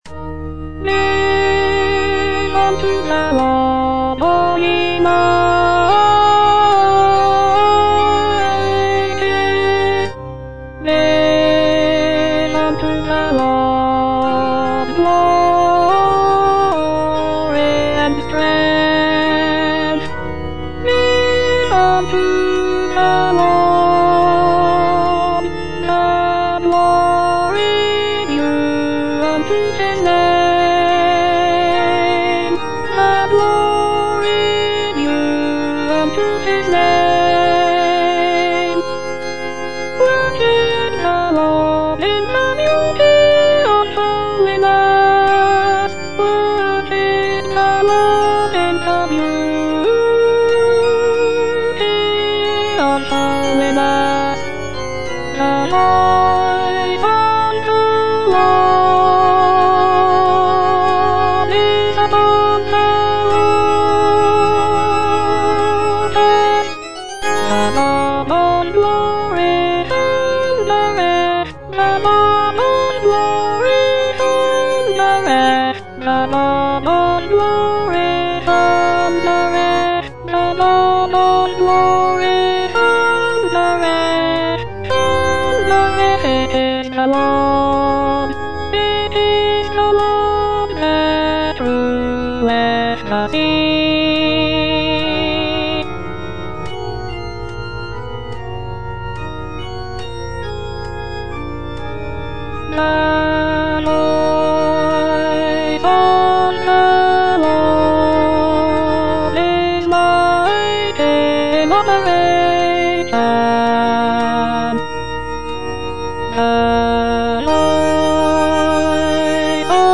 E. ELGAR - GIVE UNTO THE LORD Alto I (Voice with metronome) Ads stop: auto-stop